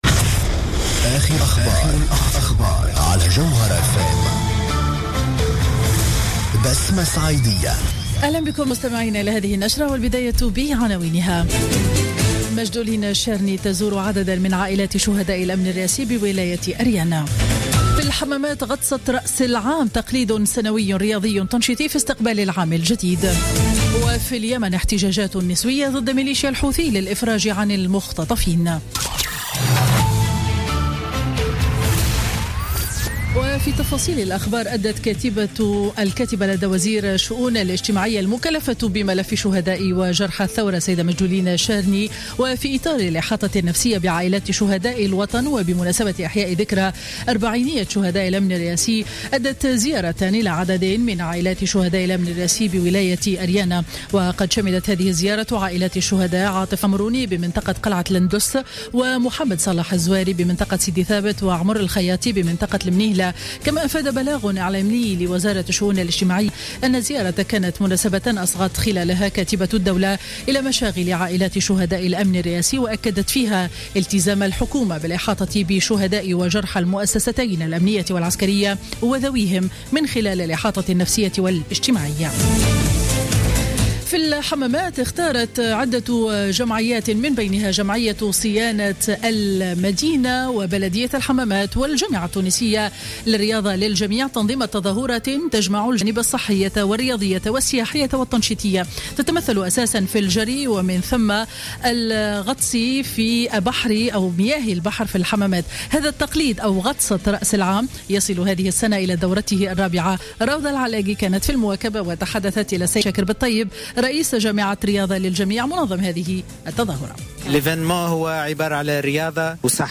نشرة أخبار منتصف النهار ليوم الجمعة 01 جانفي 2016